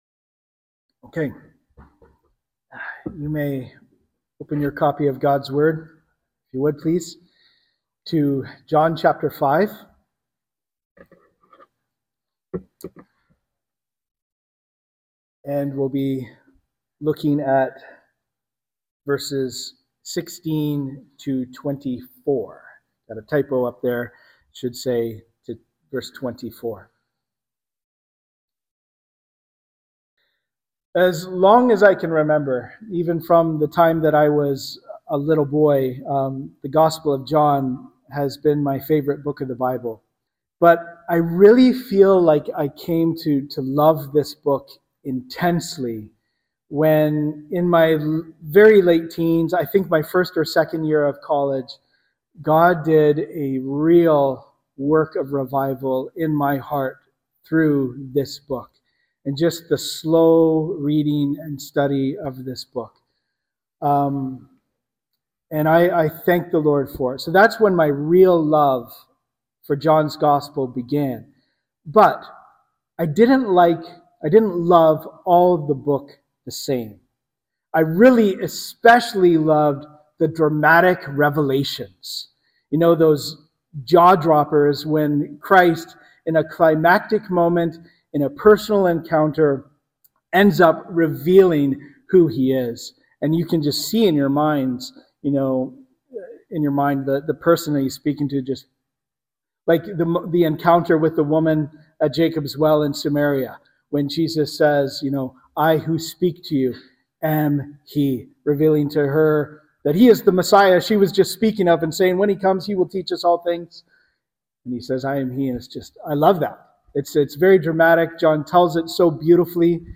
Sermons - Cannington Baptist Church